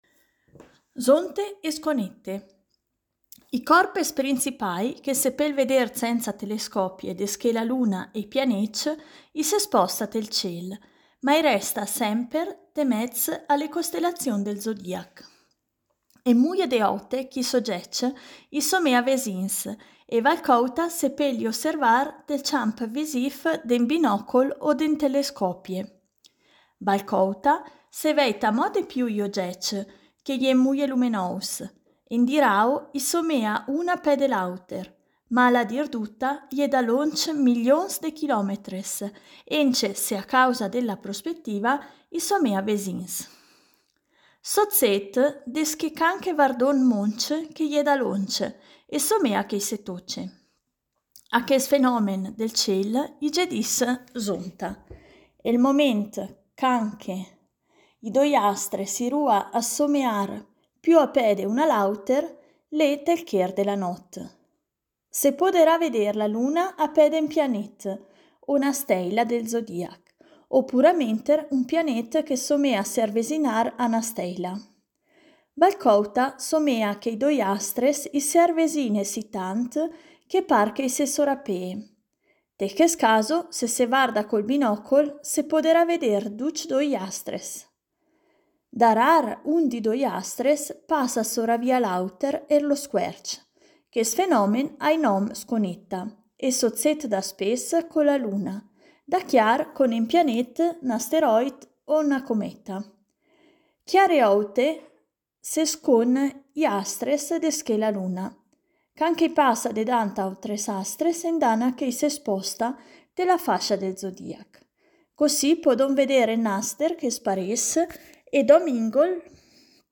Il progetto “Idiomi celesti” promuove l’osservazione ad occhio nudo del cielo stellato con testi scritti e letti ad alta voce in lingua ladina e in dialetto lumezzanese.